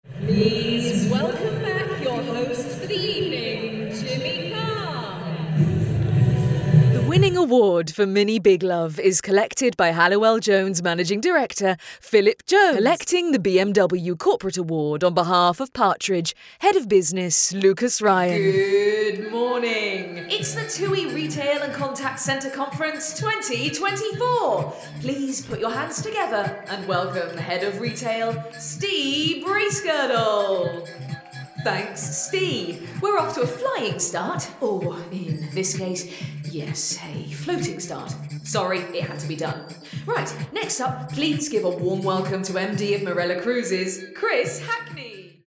Female
English (British)
My natural vocal tone is sincere, friendly and direct with a clarity and warmth.
Words that describe my voice are Warm, Confident, Friendly.